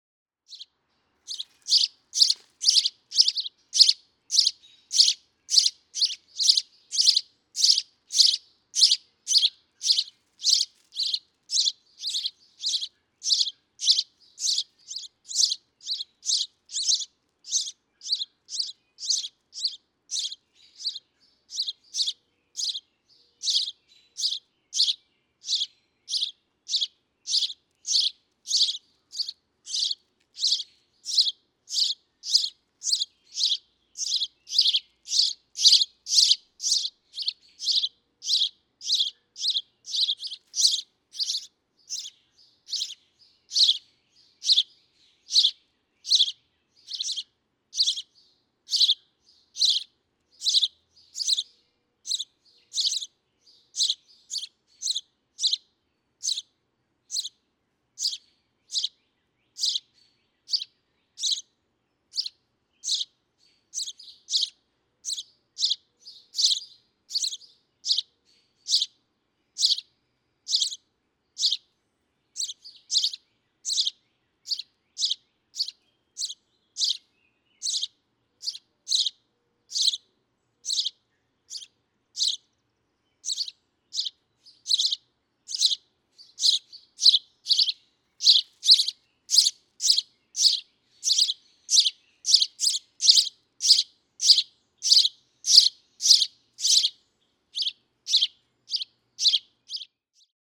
House sparrow
♫367. Calls of nestling house sparrows are surprisingly song-like.
367_House_Sparrow.mp3